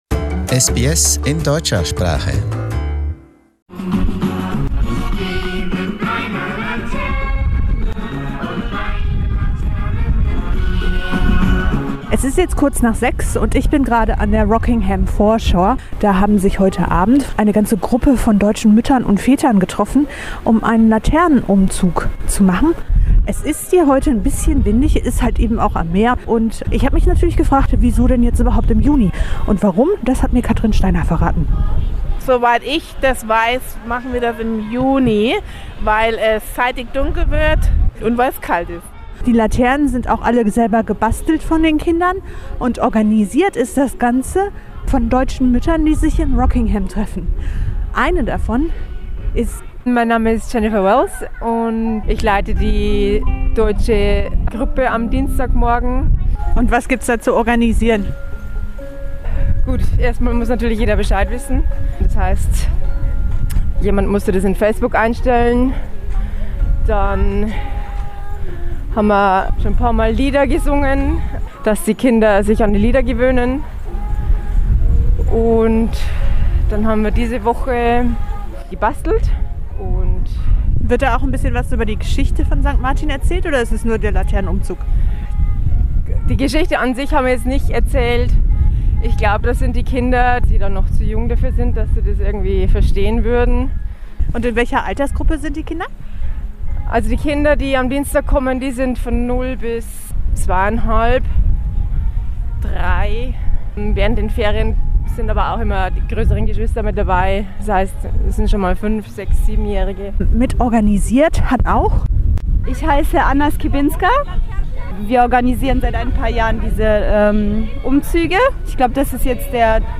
Deutsche Familien kamen letzten Freitag an der Rockingham Foreshore (WA) mit ihren selbstgebastelten Laternen für einen Umzug zusammen.